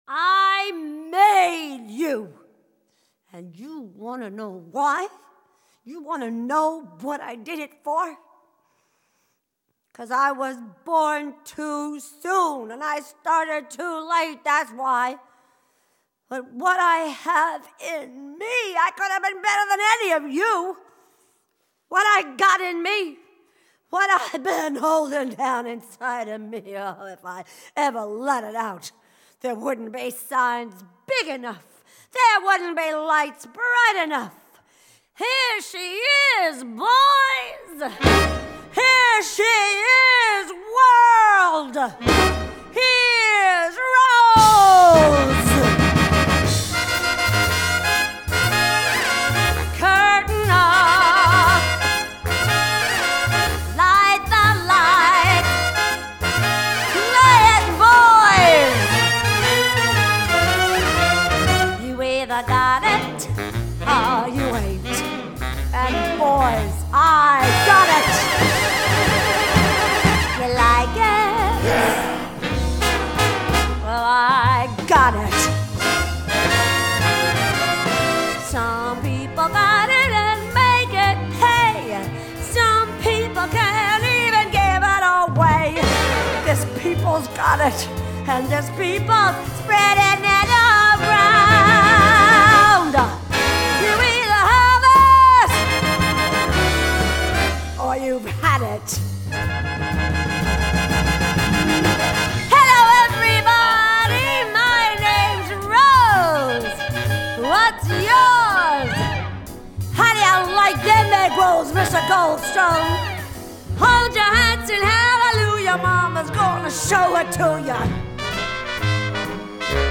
1959   Genre: Musical   Artist